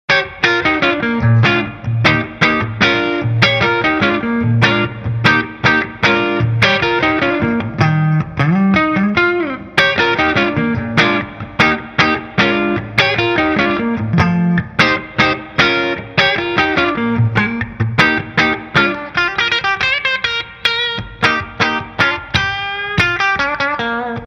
Inflames é um falante com voice britânico inspirado em um dos mais renomados timbres dessa linhagem, apresenta harmônicos extremamente detalhados e complexos, graves encorpados e definidos, alcance médio rico e detalhado com características do timbre usado por Slash, Steve Stevens e Peter Frampton.
CLEAN 1
Inflames-clean.mp3